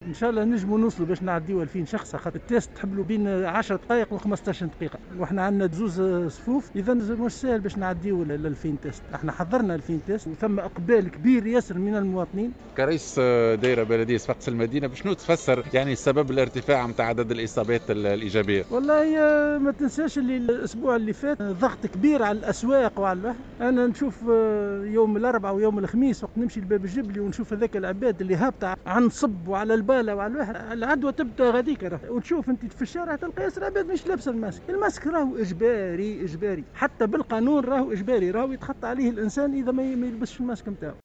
أعرب رئيس الدائرة البلدية لصفاقس منير العفاس في تصريح لمراسل "الجوهرة أف أم" عن أمله في بلوغ عدد كبير من الاشخاص المستهدفين بالتحليل السريع في صفاقس في اطار حملة تقصي سريع لكورونا اليوم الأحد، مشيرا إلى أنه تم تحضير ألفي فحص سريع.